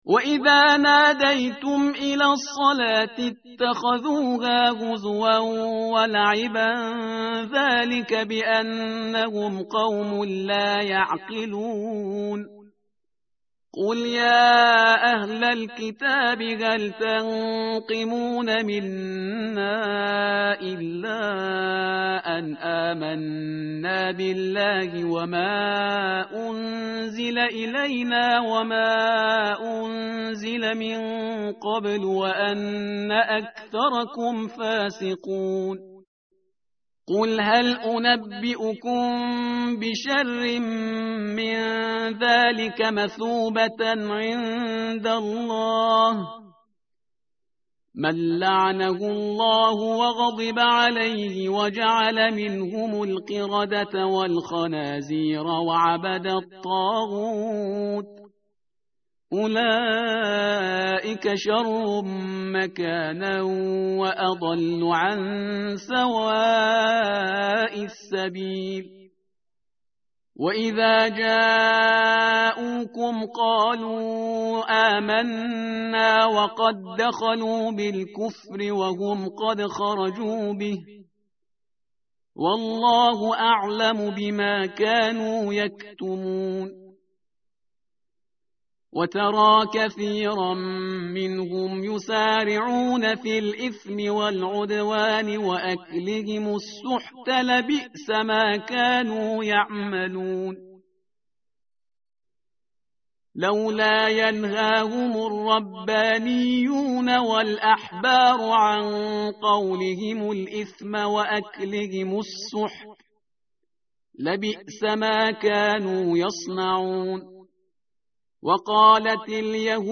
tartil_parhizgar_page_118.mp3